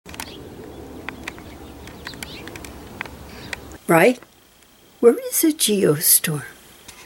Throughout much of the video there will be a clip of Songbird speak followed by my attempt to imitate the Songbird accent while speaking what seem to me to be the words they just spoke.